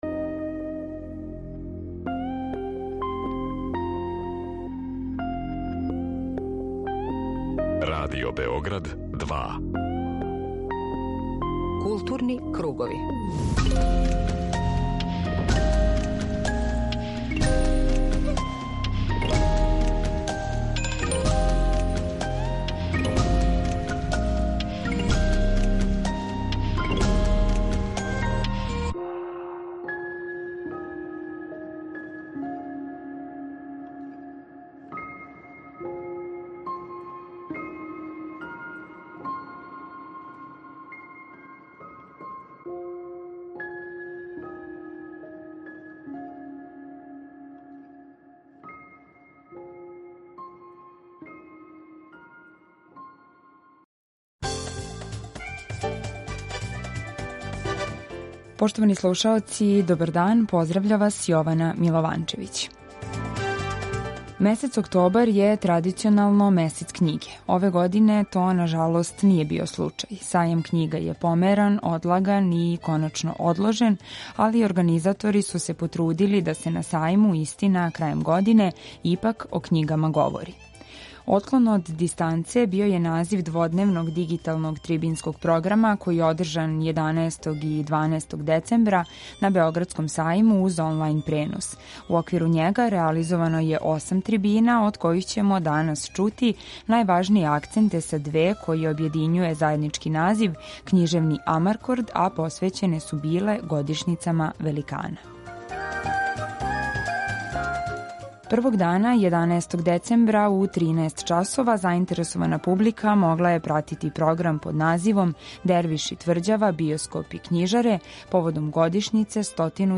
Отклон од дистанце назив је дводневне дигиталне трибине која је одржана 11. и 12. децембра као својеврсна замена за Међународни београдски сајам књига. У оквиру овог програма реализовано је осам онлајн трибина, а у данашњој специјалној емисији чућемо најважније акценте са две које обједињује заједнички назив Књижевни амаркорд , посвећен годишњицама великана.